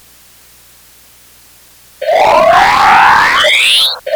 La elipse que representa el anillo aparece desde el margen inferior izquierdo hasta el margen superior derecho. Dicha elipse es tan estrecha que el sonido obtenido se parece más al que generaría una línea ancha y borrosa de un lado a otro de la imagen. Nada más escuchar la parte izquierda de la elipse se incorpora el sonido generado por el cuerpo del planeta. Finalmente, se sonifica el lado derecho del anillo.